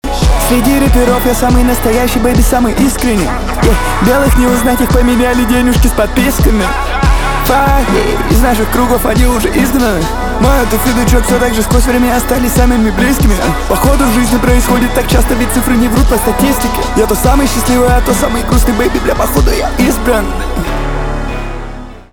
русский рэп , битовые , басы , крутые , качающие